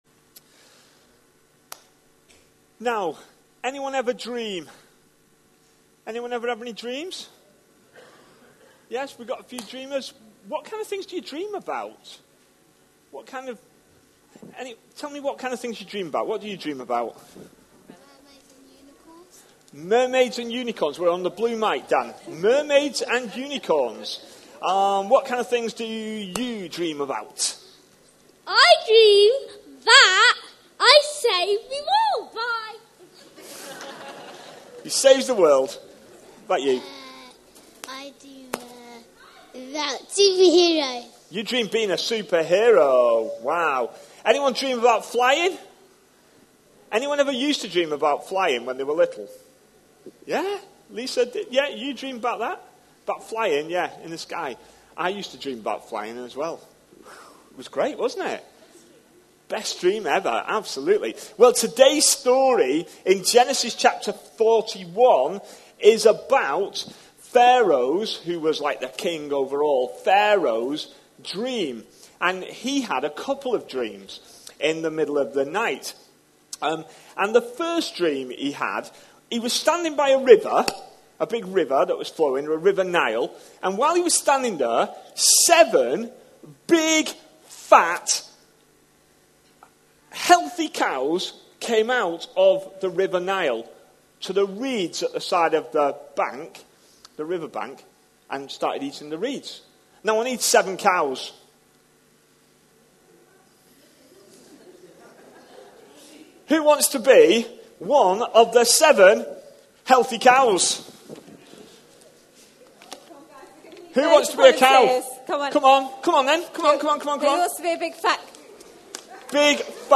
A sermon preached on 26th May, 2019.
Genesis 41:25-30 Listen online Details This all-age service talk is based on the stories of Joseph in Genesis chs 39-41; the reading is Gen 41:25-30 (seven years of feast and famine).